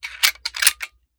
7Mag Bolt Action Rifle - Loading Rounds 002.wav